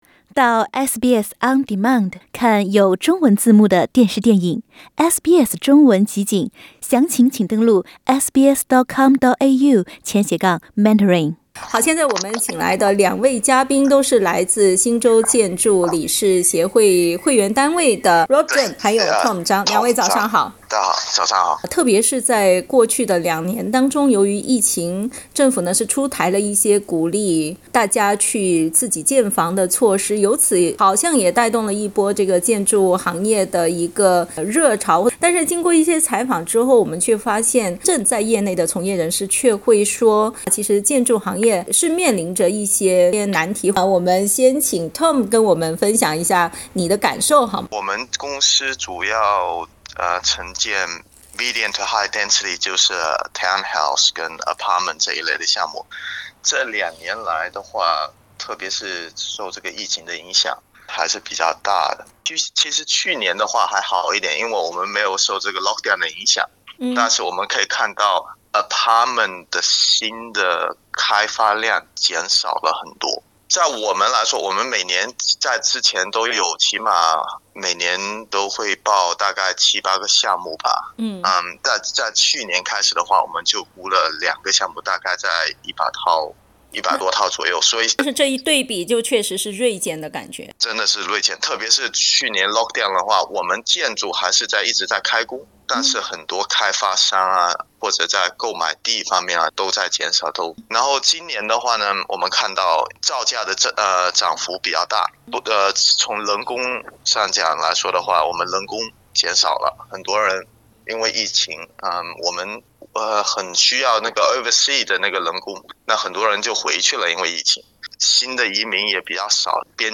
兩位悉尼建築公司高管接受寀訪時，不約而同地彊調市場穩定的重要性。